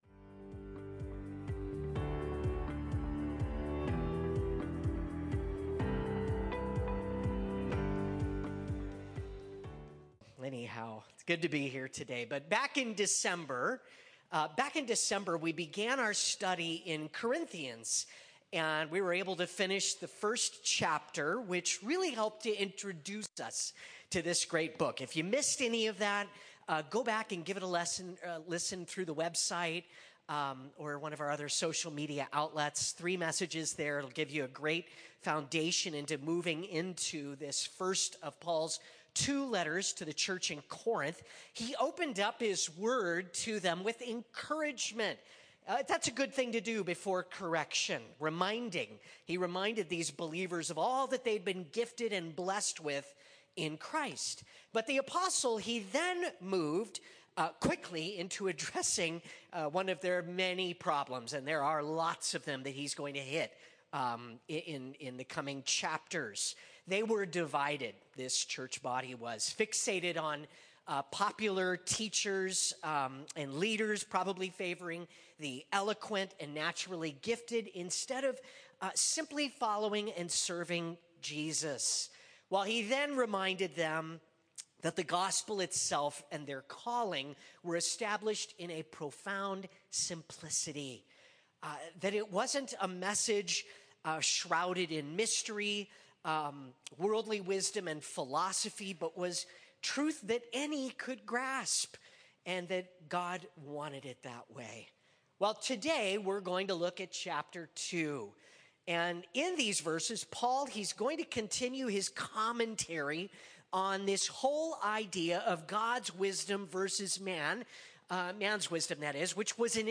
… continue reading 25 episod # Religion # Calvary # Chapel # Christianity # Sermons